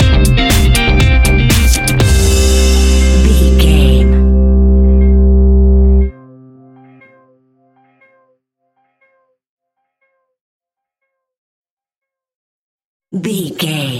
Aeolian/Minor
driving
energetic
electric guitar
bass guitar
synthesiser
drum machine
piano
funky house
electro funk
upbeat
synth leads
Synth Pads